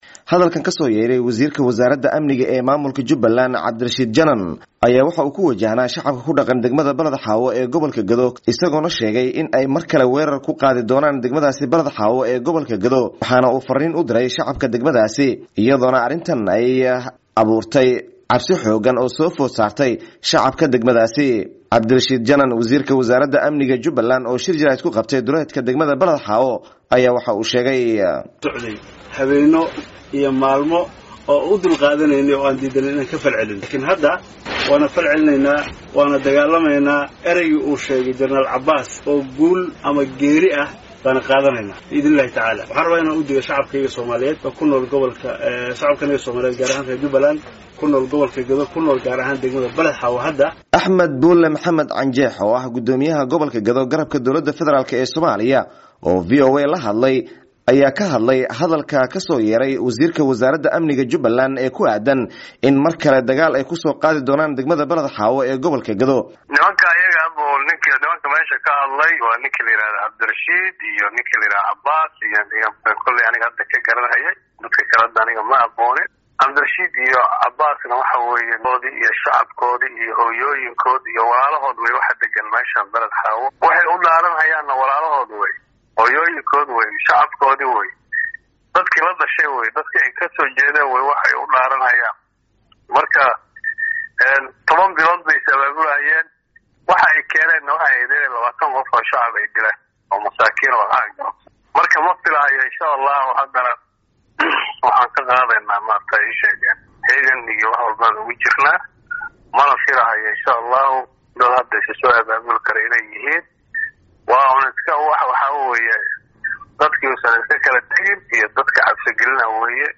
Warbixinta xiisadda Baladxaawo